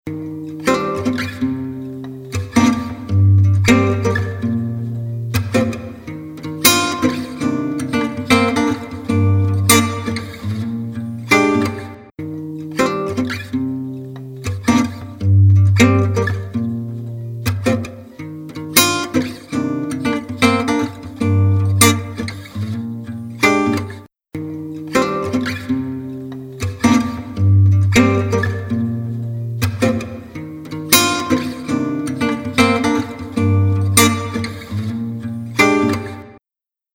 UltraReverbには、あらゆる音楽的ニーズに応えるべく、多様な楽器やユースケースを紹介するために手作業で作成された、様々なファクトリープリセットが付属しています。
UltraReverb | Acoustic Guitar | Preset: String Plate
UltraReverb-Eventide-Acoustic-Guitar-Strings-Plate.mp3